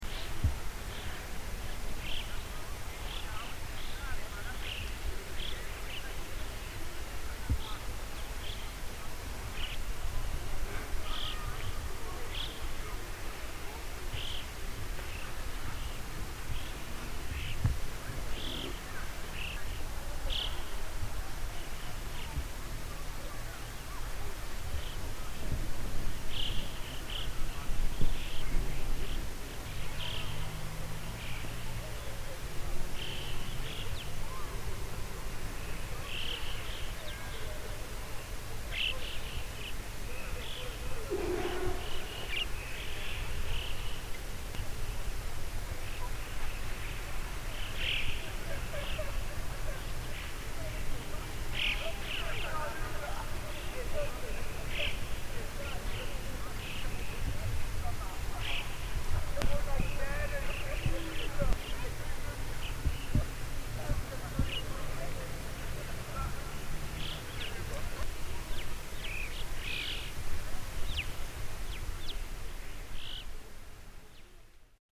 Birds nesting in the Dolomites
Facebook Twitter Headliner Embed Embed Code See more options 2,400 metres up in the Italian Dolomites, we tune into the sounds of birds nesting and wheeling overhead as we climb the mountain paths. The sound of the birds reverberates for many hundreds of metres across this peaceful scene, and we can hear the sound of a few fellow walkers 200m below us punctuating the serenity.